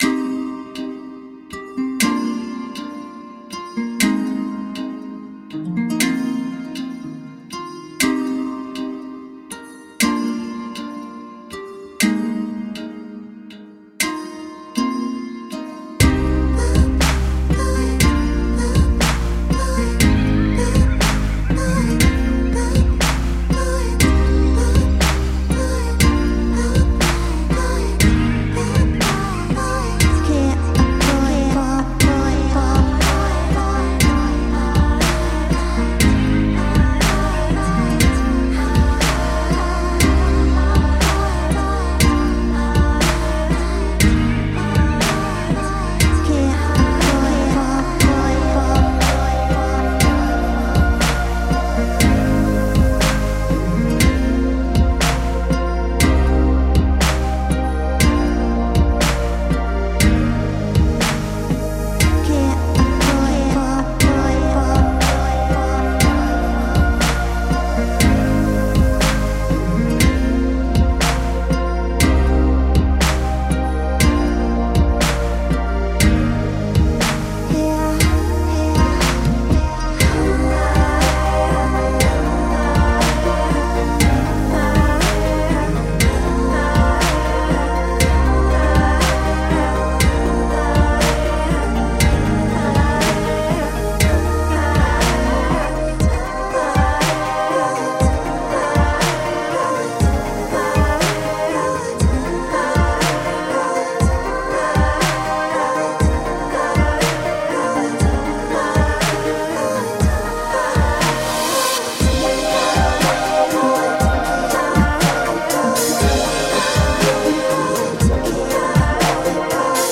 A world of independent music with electronic spice.
A mix of world music and contemporary electronica.
Tagged as: World, Folk